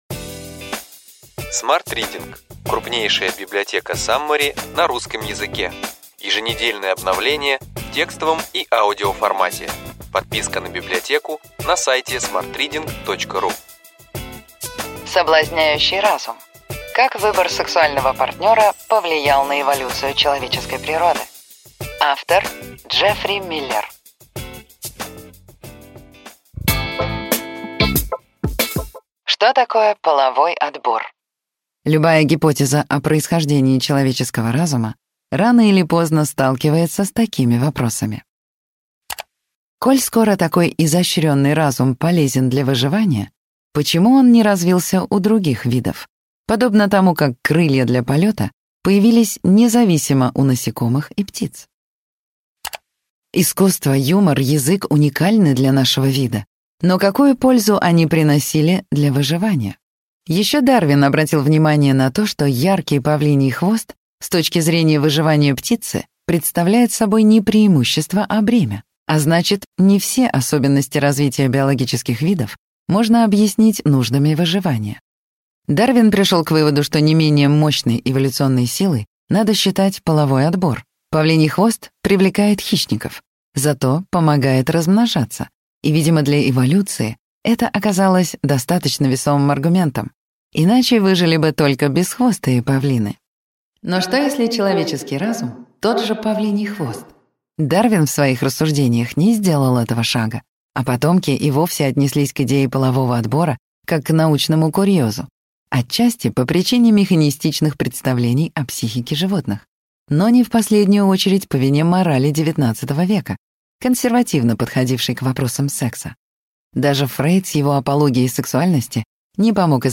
Аудиокнига Ключевые идеи книги: Соблазняющий разум. Как выбор сексуального партнера повлиял на эволюцию человеческой природы.